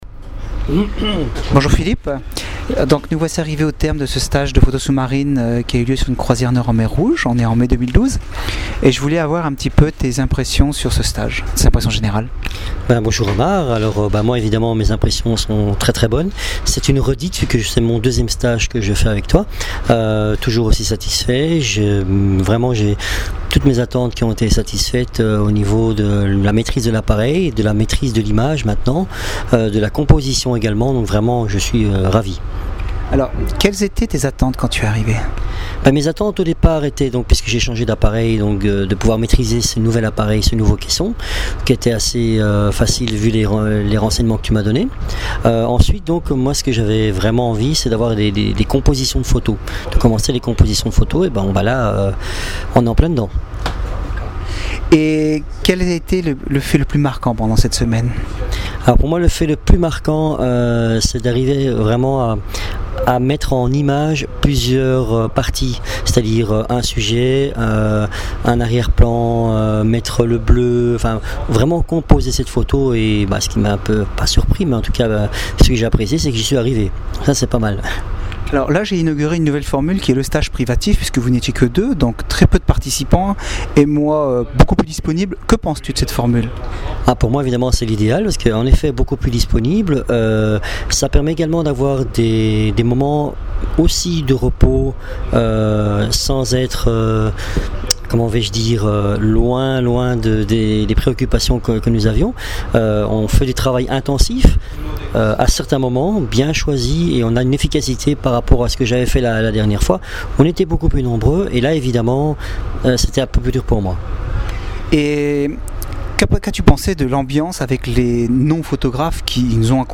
Le commentaire écrit et oral des stagiaires